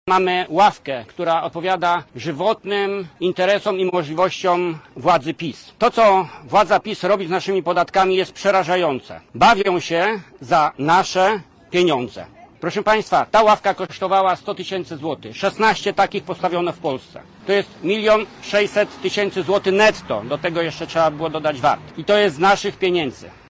Konferencję prasową na Placu Teatralnym senator rozpoczął cytatem z „Misia” Stanisława Barei.
• mówi Jacek Bury senator Polski 2050.